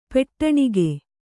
♪ peṭṭaṇige